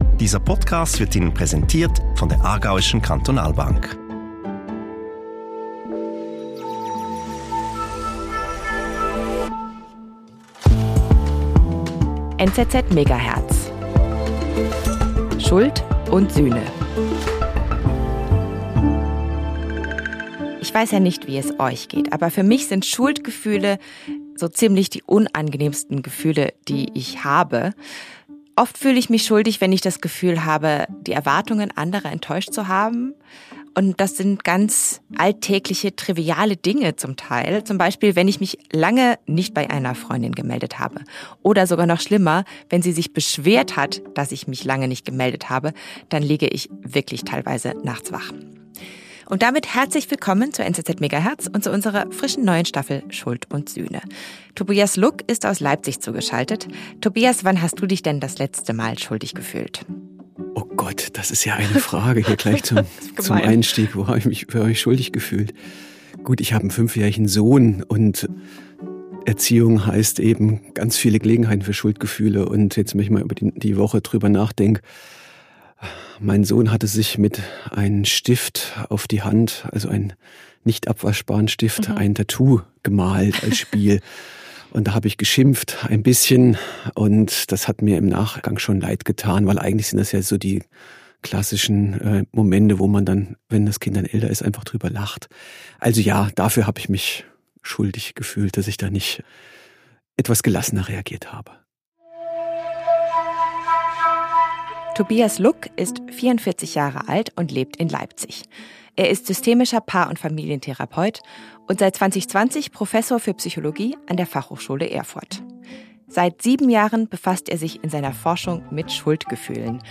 Unsere Hosts fragen sich das auch und sprechen mit Menschen, die Antworten gefunden haben. Jede Staffel nehmen wir uns ein grosses Thema vor und decken überraschende Fakten und Perspektiven auf.